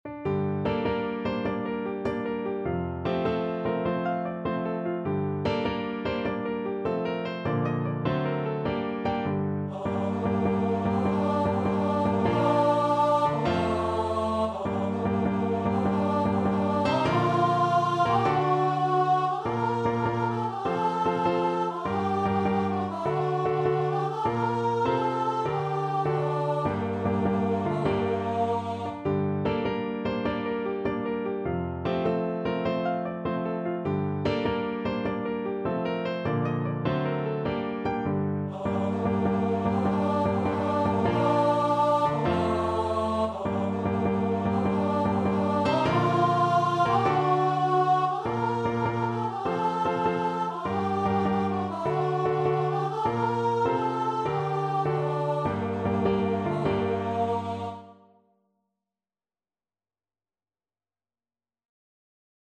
6/8 (View more 6/8 Music)
.=100 With spirit